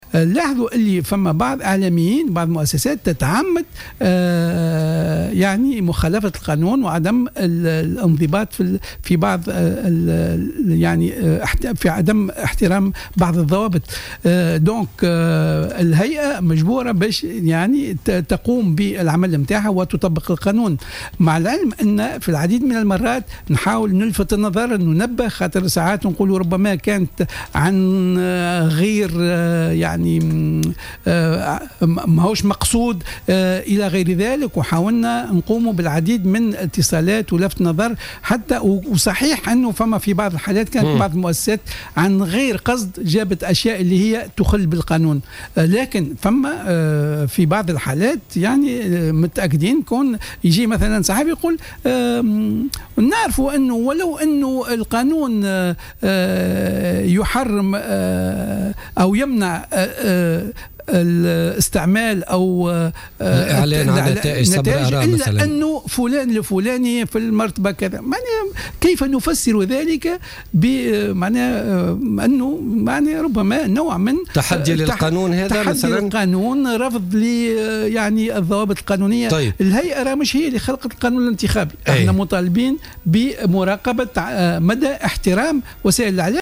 قال رئيس الهيئة العليا المستقلة للاتصال السمعي البصري،النوري اللجمي،ضيف برنامج "بوليتيكا" اليوم الأربعاء إن بعض وسائل الإعلام تتعمّد خرق الضوابط المهنية و الأخلاقية والقانونية.